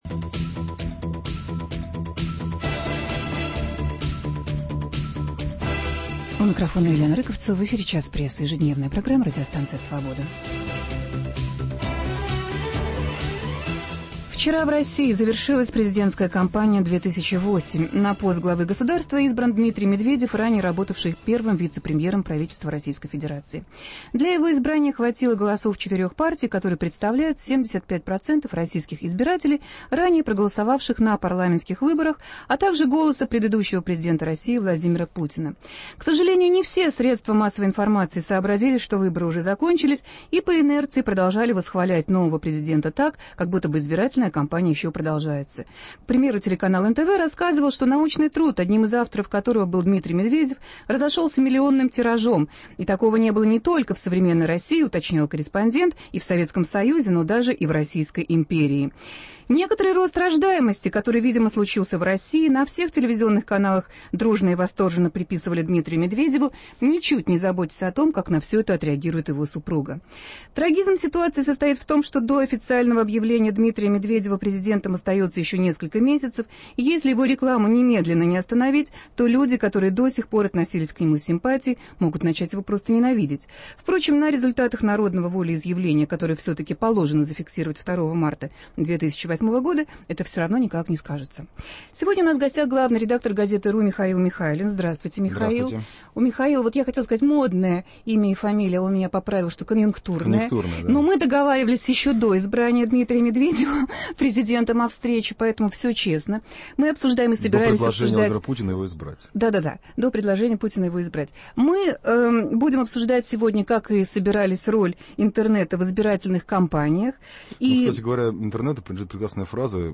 Роль интернета в избирательных кампаниях. Гость студии